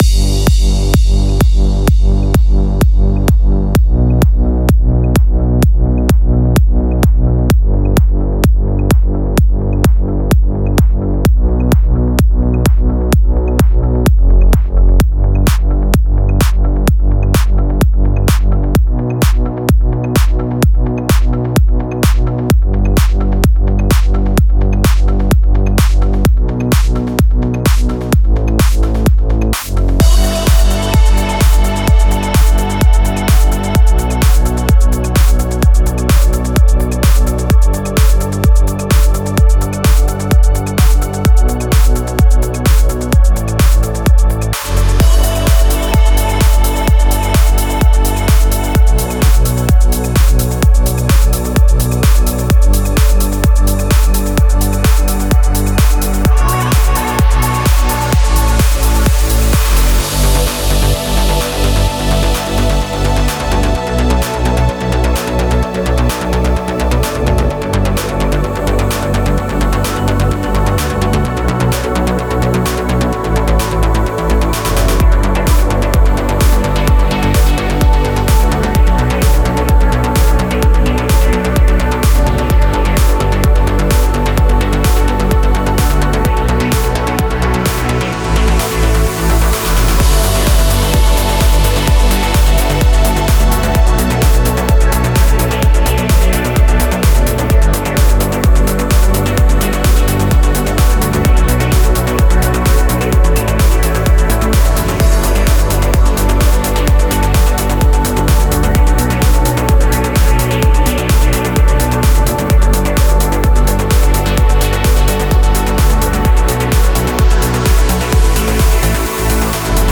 Стиль: Progressive Trance